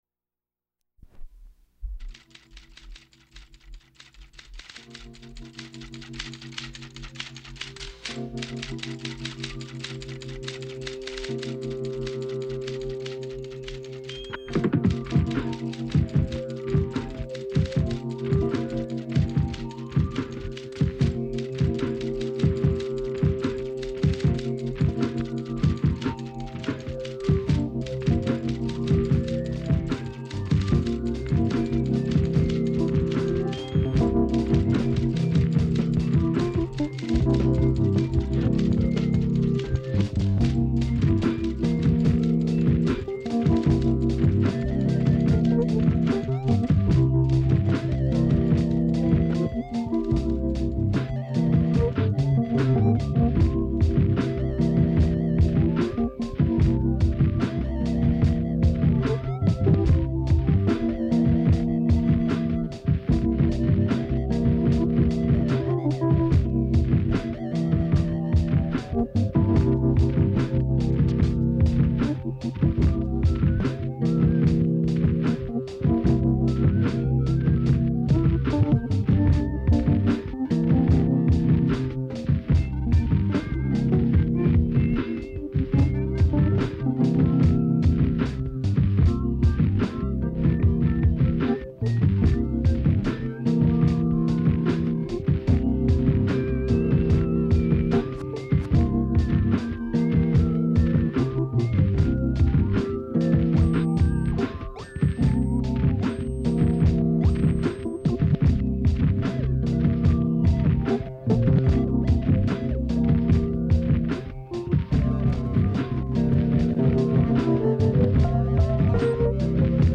• Audiocassette